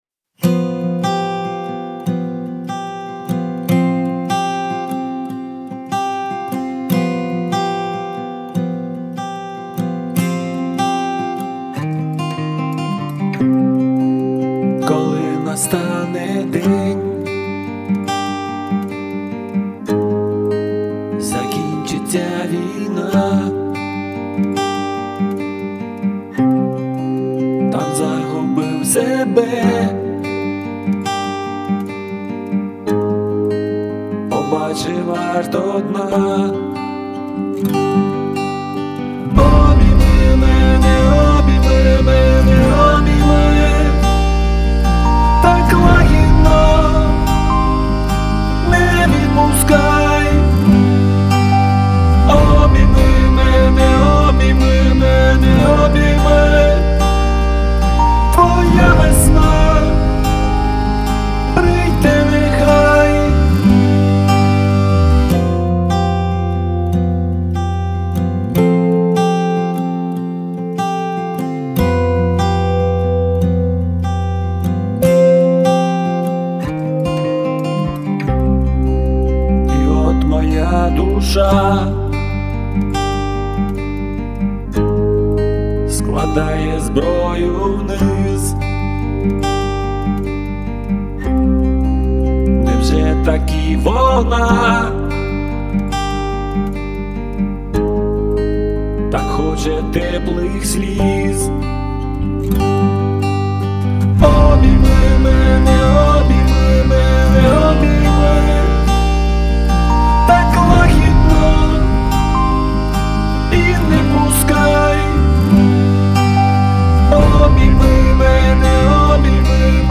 зато какие краски в голосе!